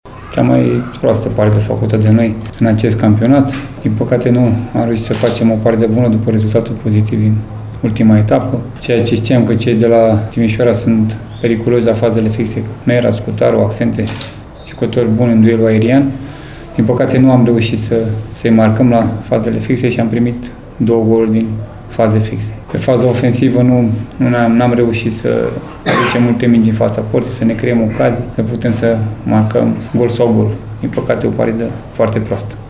În schimb, tristețe la ceilalți alb-violeți, cei din Pitești, antrenorul Nicolae Dică fiind de părere că echipa sa a făcut azi cel mai slab joc din acest sezon: